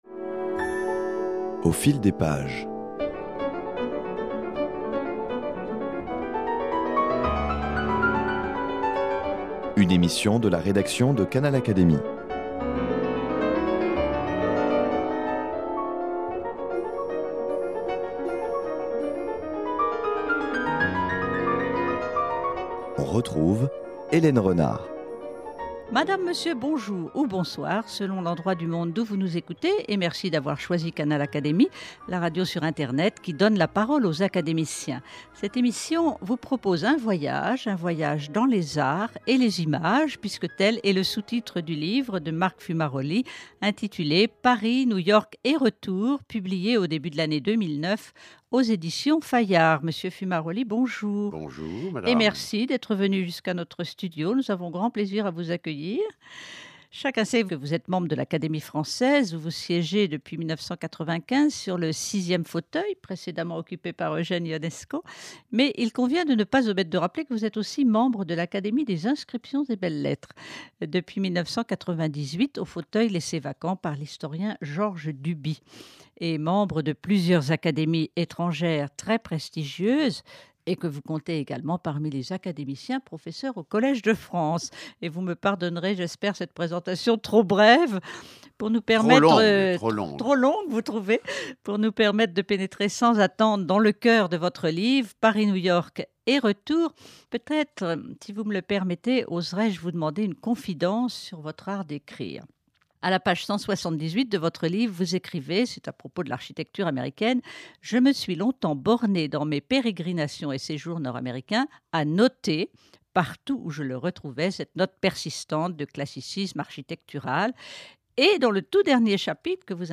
Pour commencer cet entretien, petite confidence de l'écrivain : certes, lors de ses voyages, il prend de nombreuses notes.